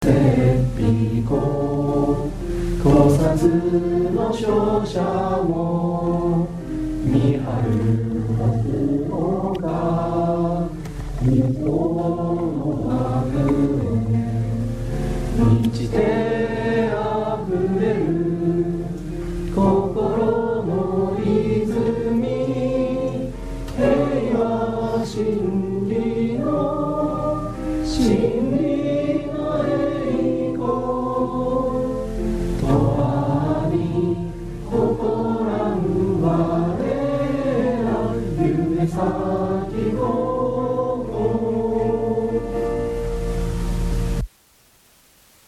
３月２３日　3学期終業式、表彰伝達
校歌斉唱の音声（ 3番）です。
校歌斉唱（３番）.MP3